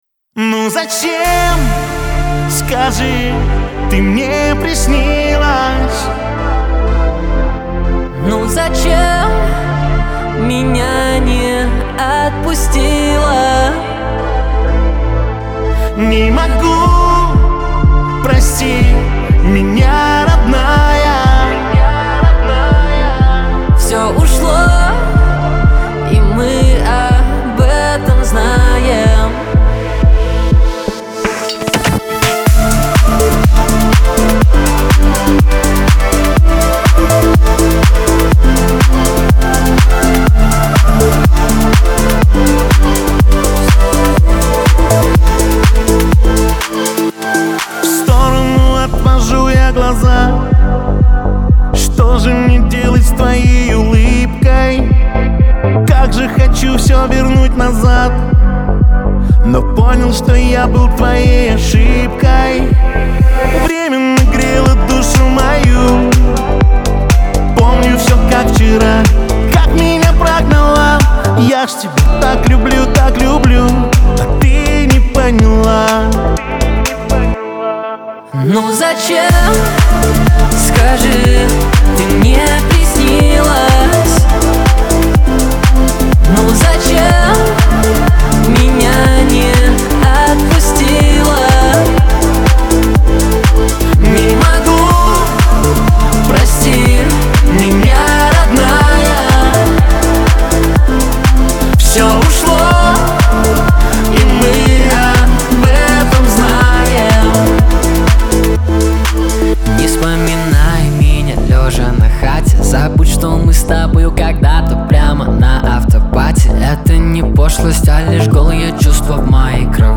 дуэт , эстрада , диско , pop
dance